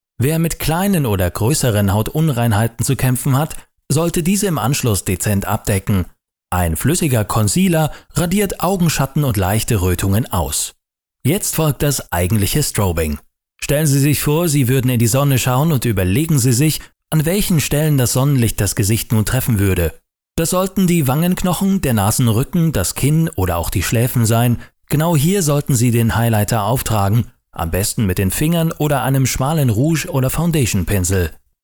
Kein Dialekt
Sprechprobe: eLearning (Muttersprache):
stimmprobe_internettutorial-strobing.mp3